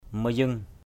/mə-zəŋ/ (d.) chổi tống (lễ Rija); thánh hóa = balai (fait d’une touffe de “ralang”) dont on se sert pour chasser les mauvais esprits = broom (made of a bunch...